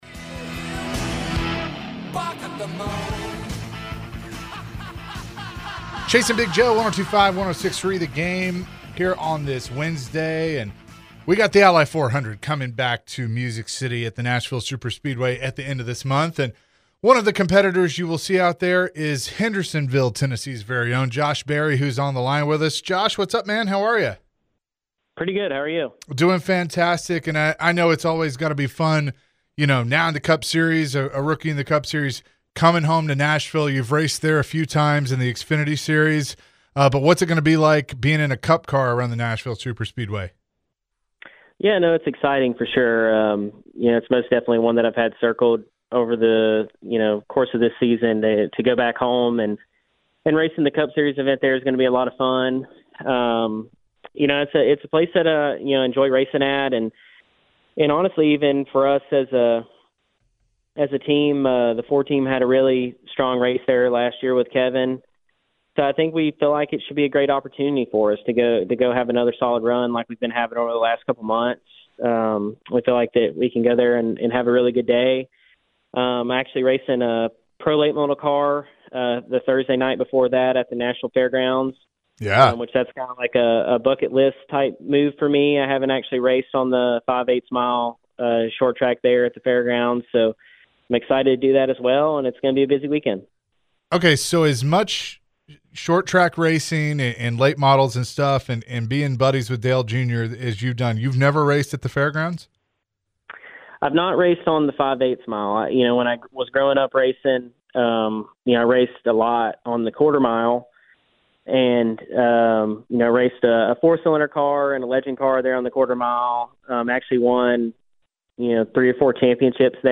Tennessee-born NASCAR Driver Josh Berry joined the show ahead of the next NASCAR race. Josh also elaborated on the recent shake-up with the racing team.